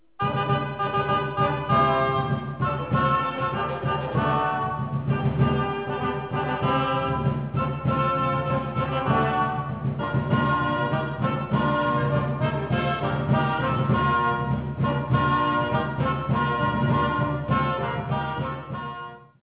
Flauti dolci
bombarda, cornetto e ghironda
bombarda e flauto dolce
bombarde
trombone
dulciana
percussione
clavicembalo e regale